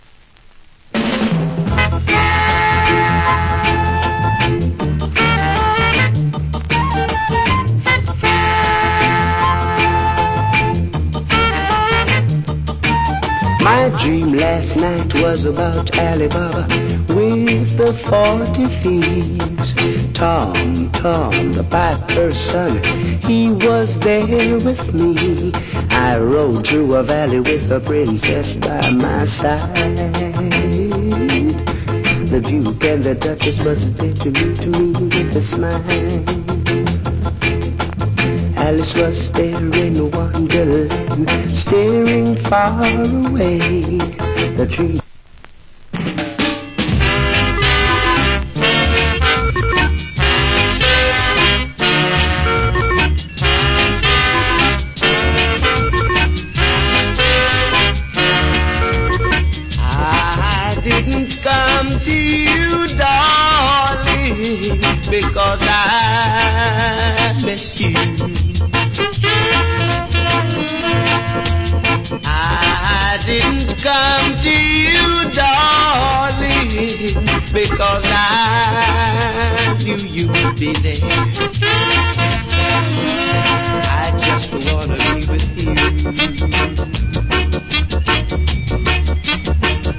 Wicked Early Reggae Vocal.